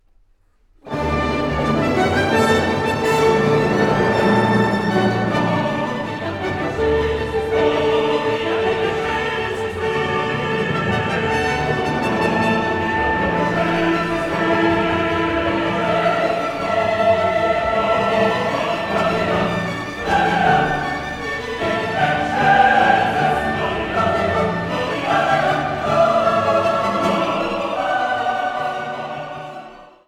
für vier Solostimmen, Chor und Orchester